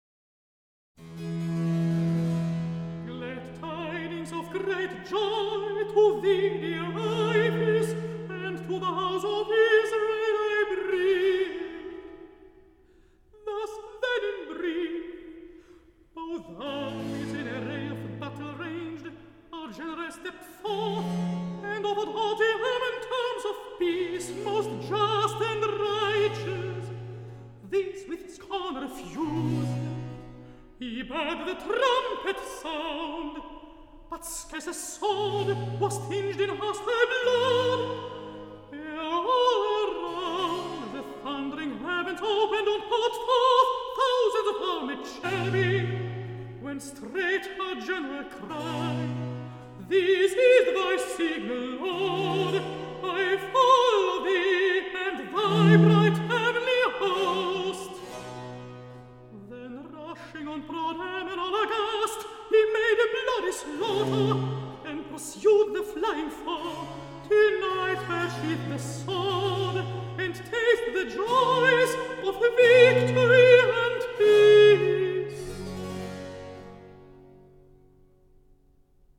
OLED SIIN ▶ muusika ▶ Klassika